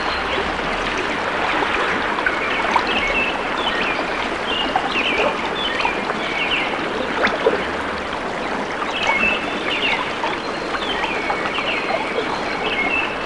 Birds And Water Sound Effect
Download a high-quality birds and water sound effect.
birds-and-water.mp3